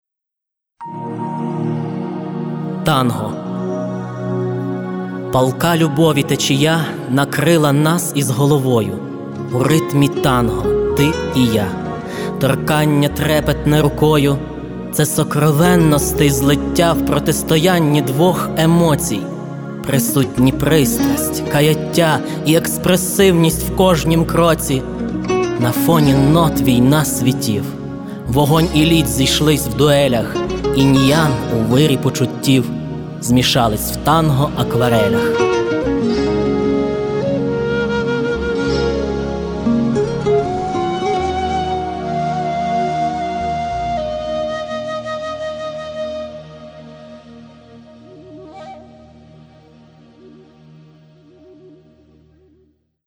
Прекрасно! 12 Така вишукана лірика, а чудова декламація і музичний супровід додають яскравих барв та емоцій віршеві! 16 19 22 flo11
Маленький спектакль. Прекрасная декламация give_rose